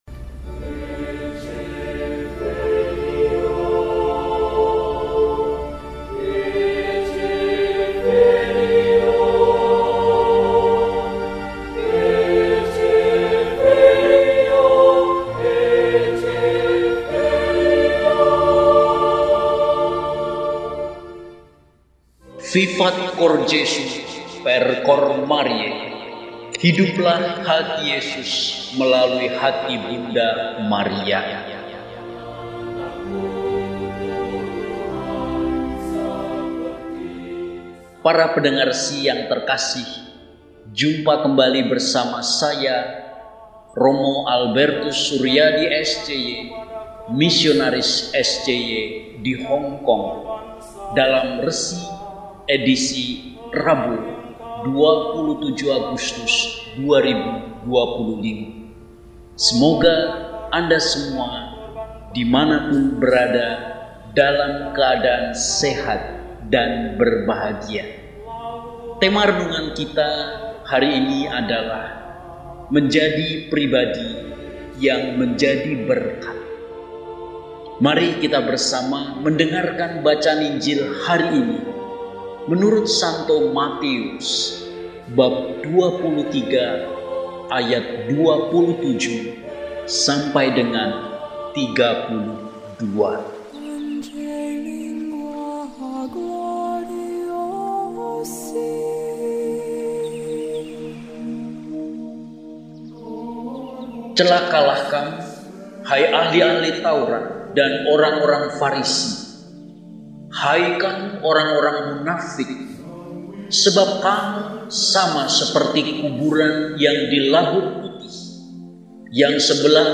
Rabu, 27 Agustus 2025 – Peringatan Wajib St. Monika – RESI (Renungan Singkat) DEHONIAN